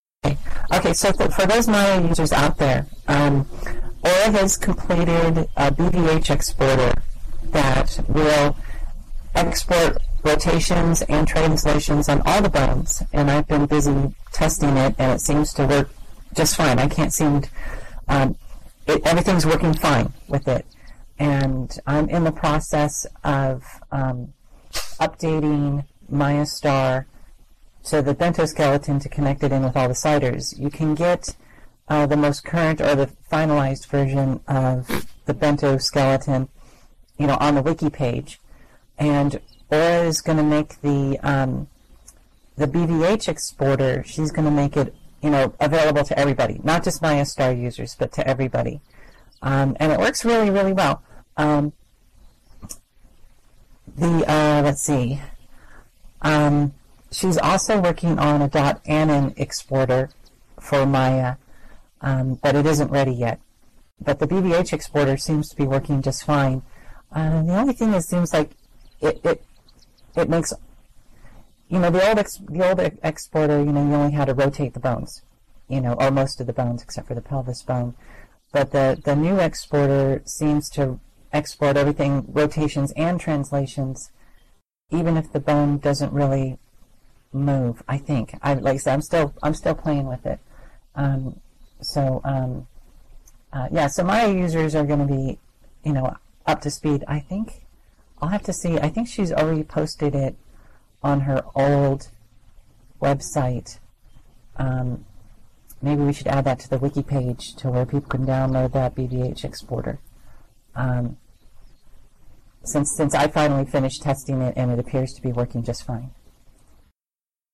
The following notes and audio were taken from the weekly Bento User Group meeting, held on Thursday, June 16th at 13:00 SLT at the the Hippotropolis Campfire Circle .